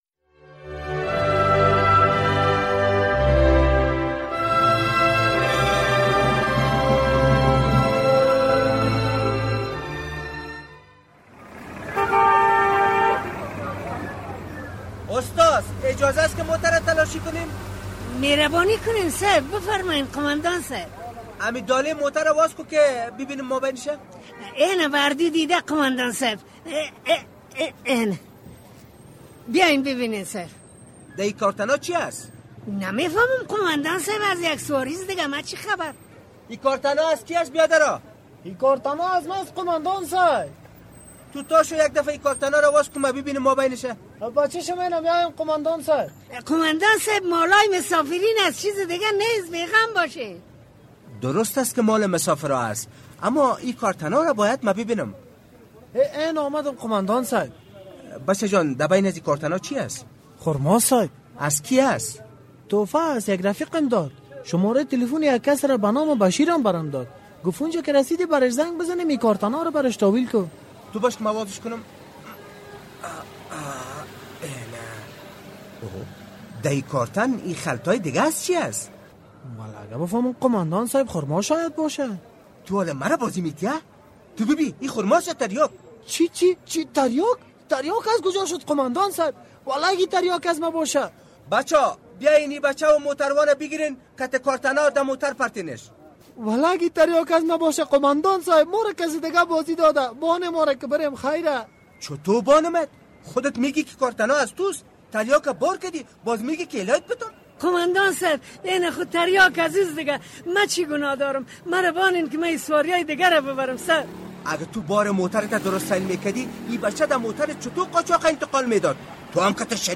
درامه